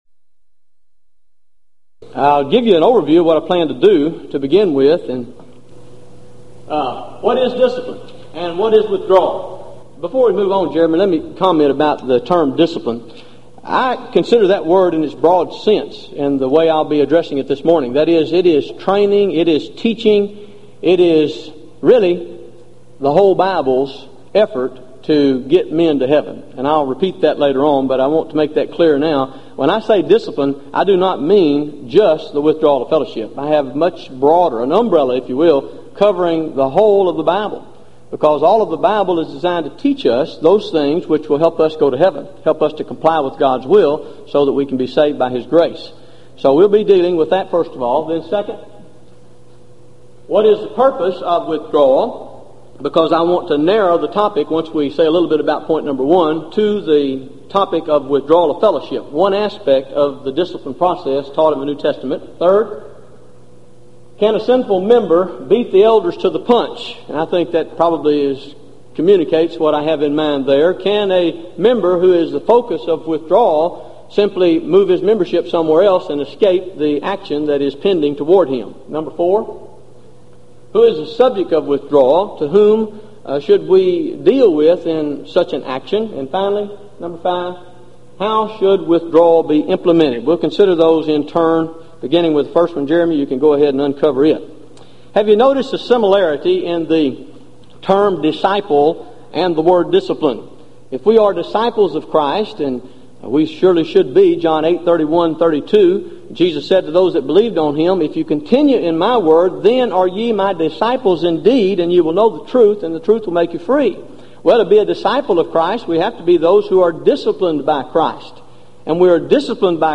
Event: 1997 Gulf Coast Lectures
lecture